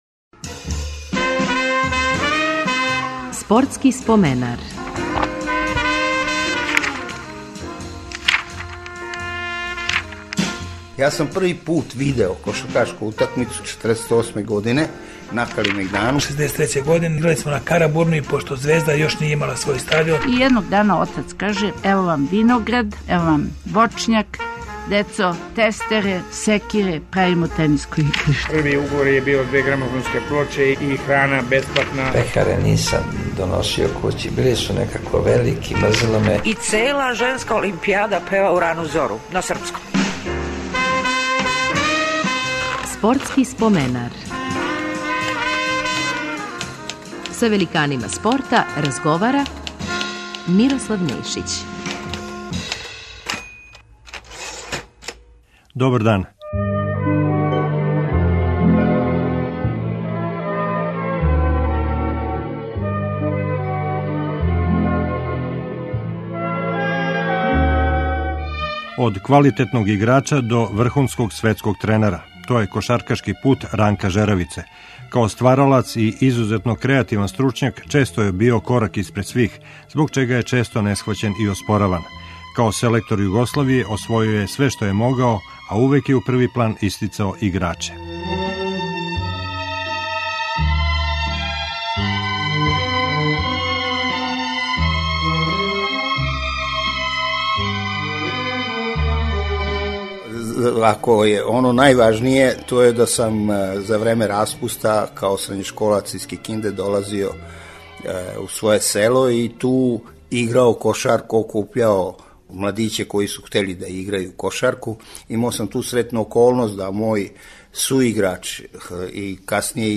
Репризираћемо разговор с кошаркашким тренером Ранком Жеравицом.